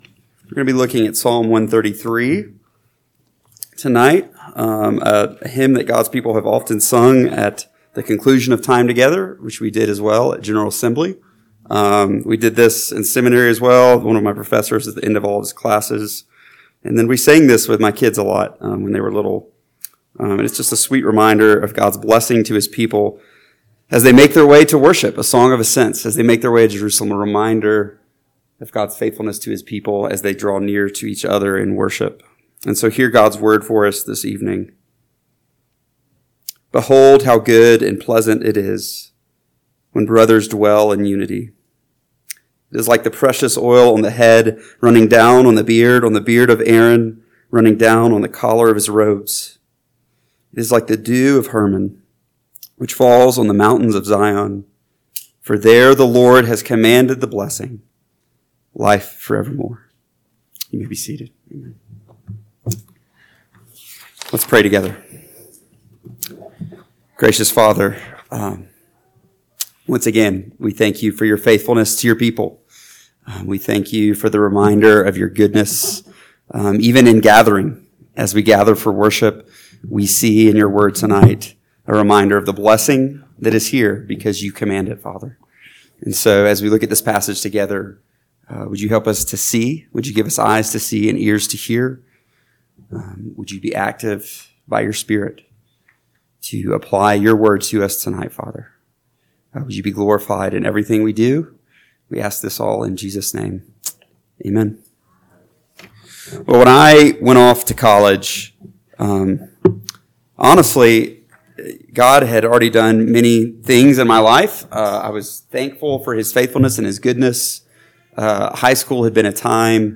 PM Sermon – 7/13/2025 – Psalm 133 – Northwoods Sermons